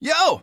Voice clip from Super Smash Bros. Ultimate